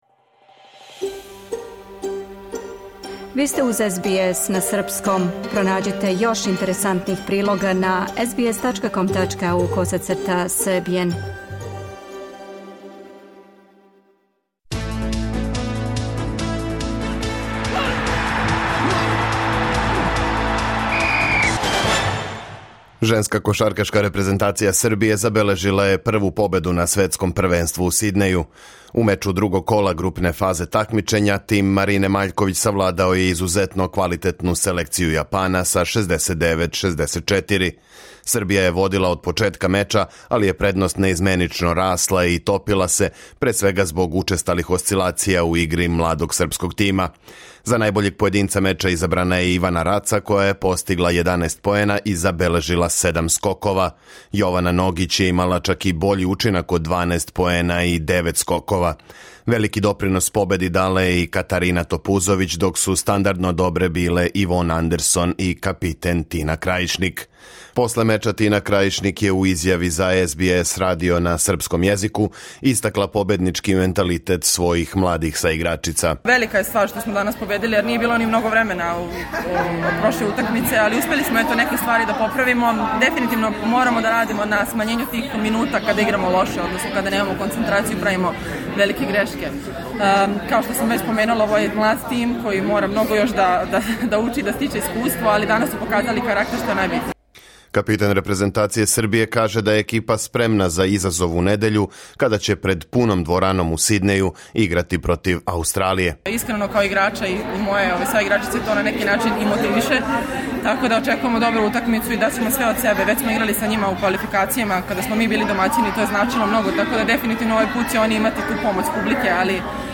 Капитен српског тима Тина Крајишник је у изјави за Програм на на српском СБС радија истакла победнички менталитет својих младих саиграчица и казала да их не брине пуна дворана, у којој ће публика углавном бити уз домаћу селекцију.